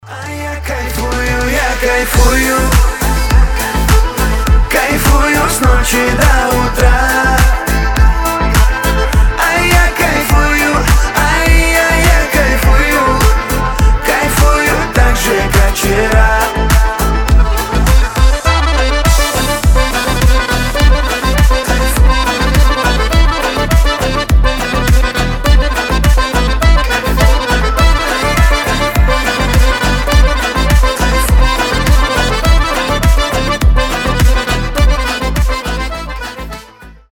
• Качество: 320, Stereo
позитивные
веселые
аккордеон
лезгинка
Веселая зажигательная нарезка на рингтон